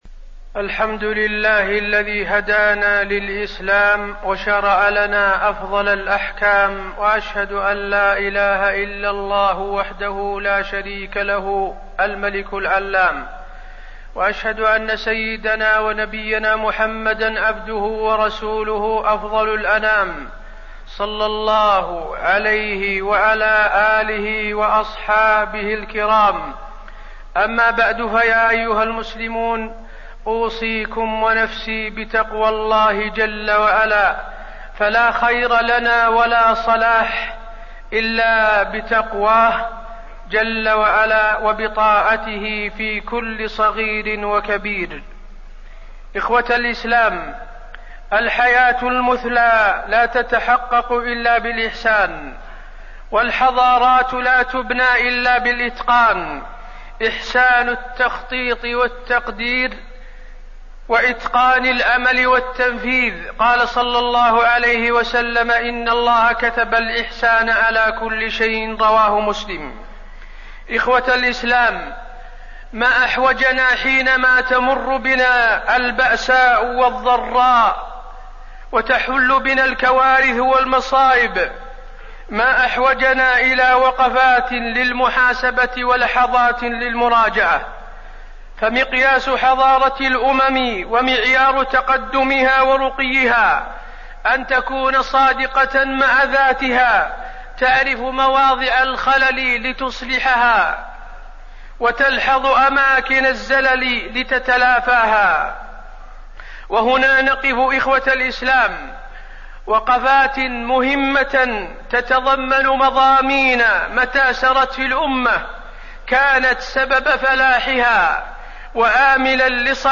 تاريخ النشر ٨ محرم ١٤٣١ هـ المكان: المسجد النبوي الشيخ: فضيلة الشيخ د. حسين بن عبدالعزيز آل الشيخ فضيلة الشيخ د. حسين بن عبدالعزيز آل الشيخ الكواريث وأسبابها